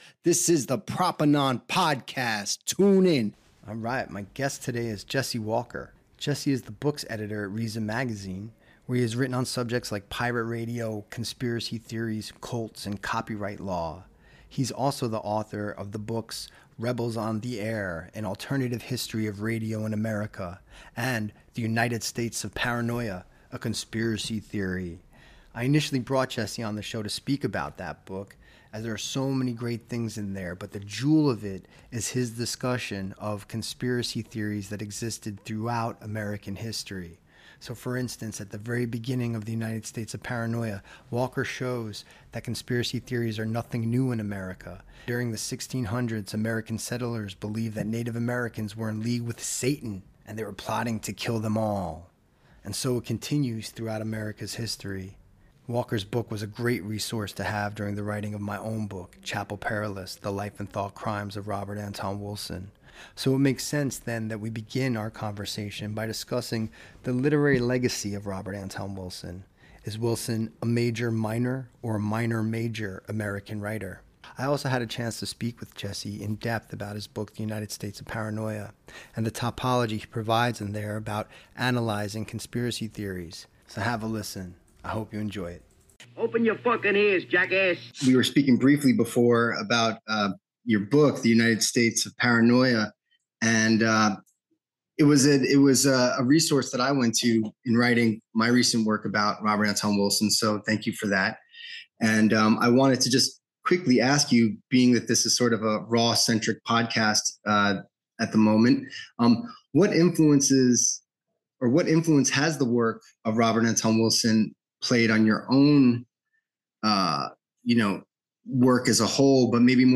The United States of Paranoia: A Conversation